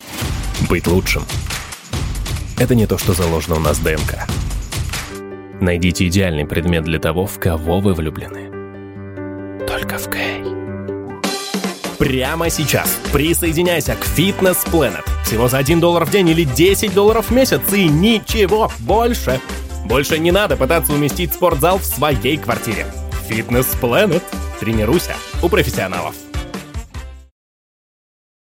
AKG P120, Behringer U22, Reaper